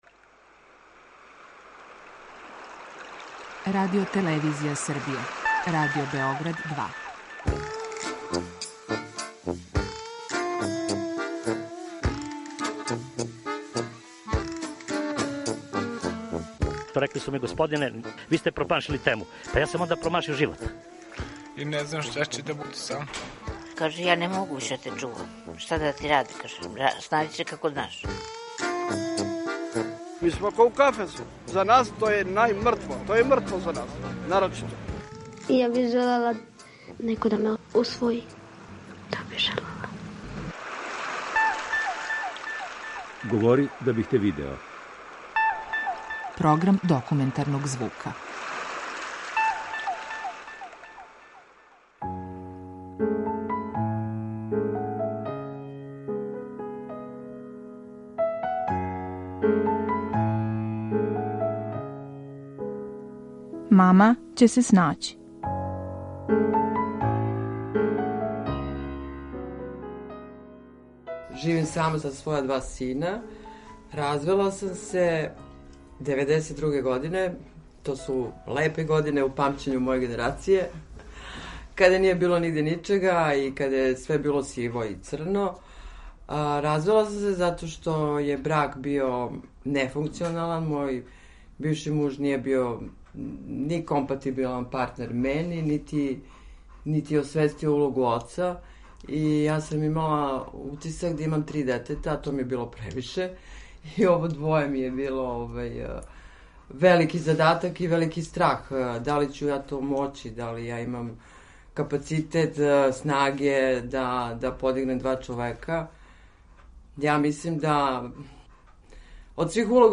Документарни програм
У данашњој репортажи чућете исповести две самохране мајке. Оне ће говорити о свим изазовима са којима се суочавају, о друштвеној осуди, практичним препрекама и својој неизмерној љубави која им даје снагу.